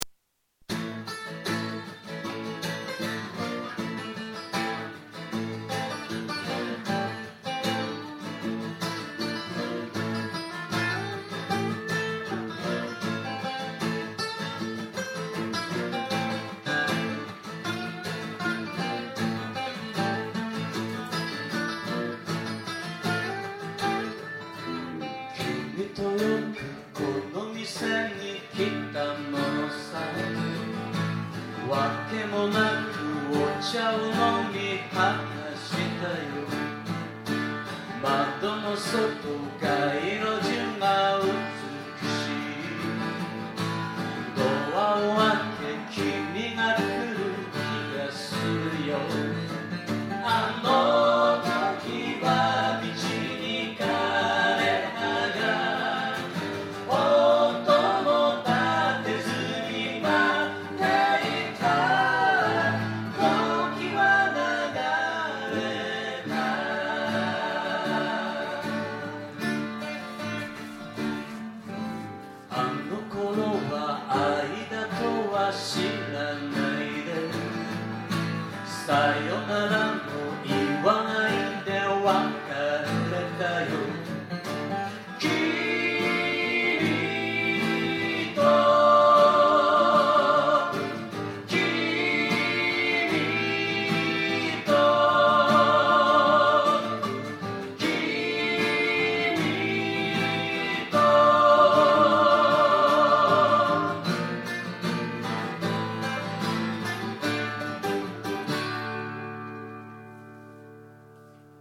愛知県名古屋市　「アートピア」
今回の録音は多少レベルが低かったので、パソコンのボリュームを